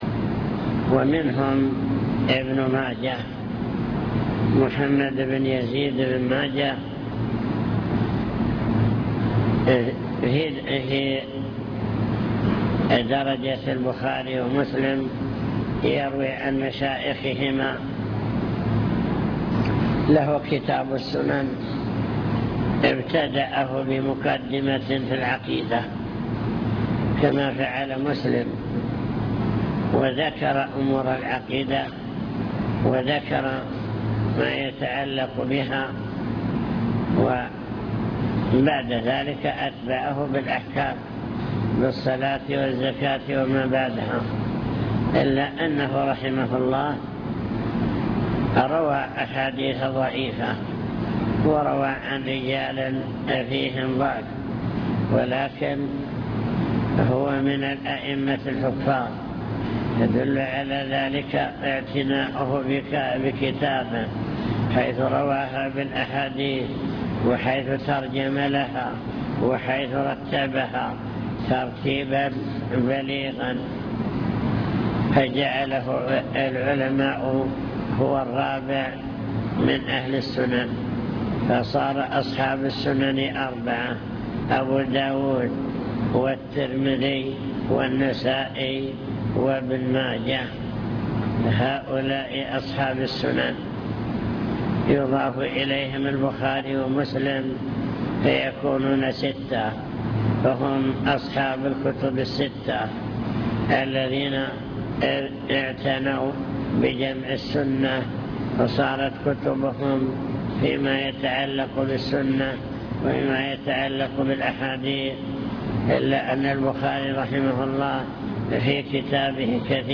المكتبة الصوتية  تسجيلات - محاضرات ودروس  محاضرات بعنوان: عناية السلف بالحديث الشريف دور أصحاب الكتب الستة في حفظ الحديث